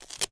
App Slide.wav